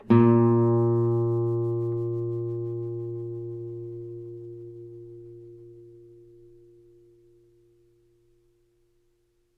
DET25-AdaptiveGuitar/Assets/AudioSources/Guitar Chords/Note_A.wav at main
Note_A.wav